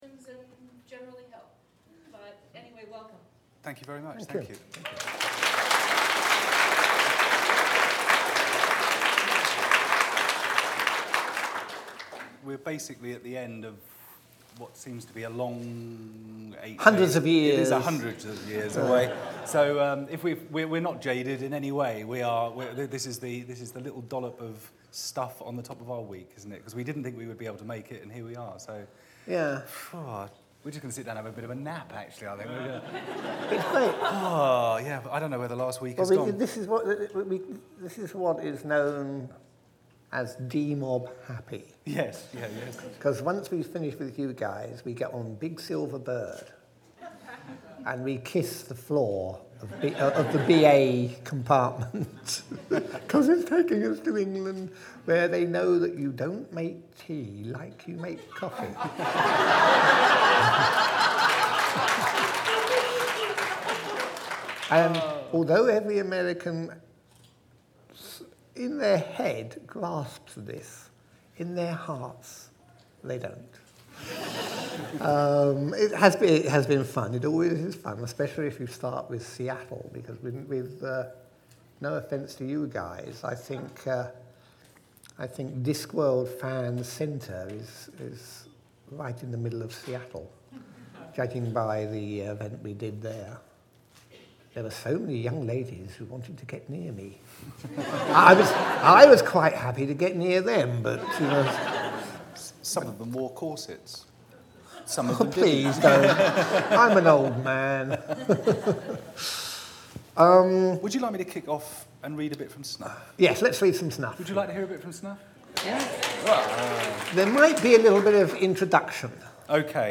To a fully packed room, Sir Terry talked about various things, including his new book, Snuff, as well as his BBC documentary on assisted death. Two selections from Snuff were read to the audience.
However, for your listening pleasure, here is an MP3 recording taken of the event. Please note that the volume is rather low, and the quality is variable.